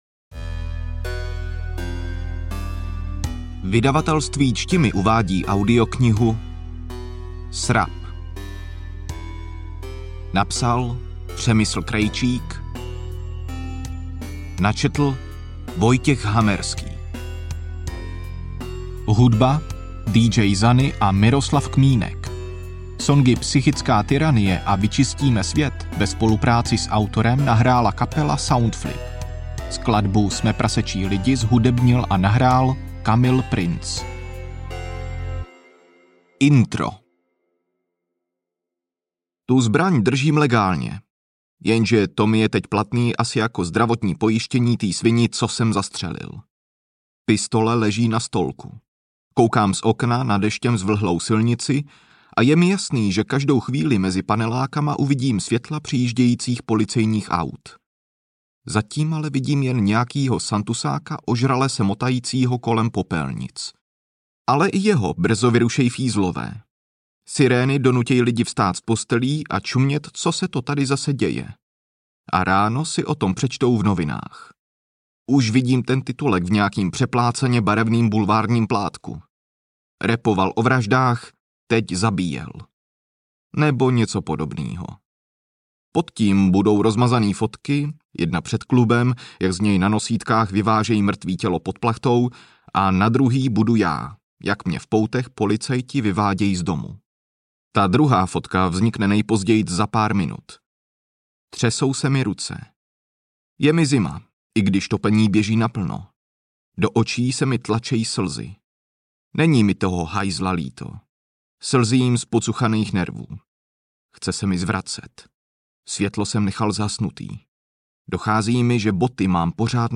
Kategorie: Román, Psychologické